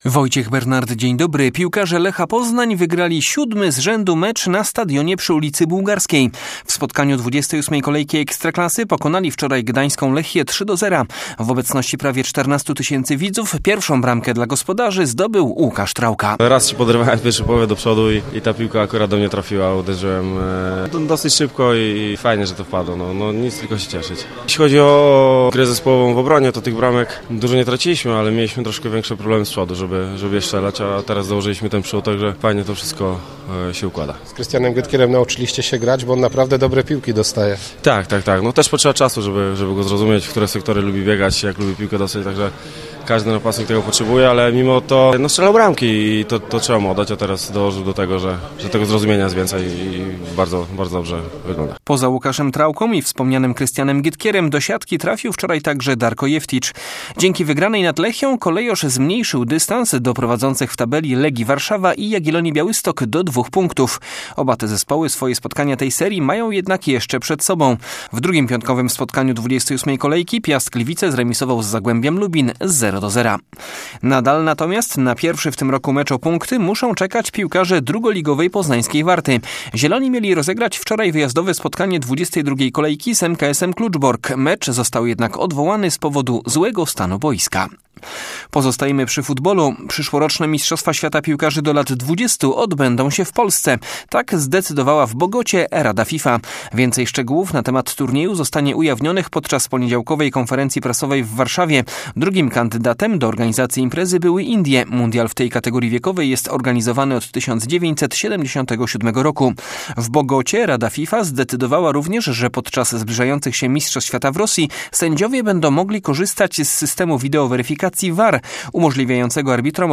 17.03 serwis sportowy godz. 7:05
W naszym porannym serwisie wracamy przede wszystkim do spotkania piłkarzy Lecha Poznań z gdańską Lechią, wygranego przez Kolejorza 3:0. Posłuchamy między innymi opinii strzelca jednej z bramek dla poznańskiej drużyny - Łukasza Trałki. Poza tym sporo informacji z zimowych aren sportowych.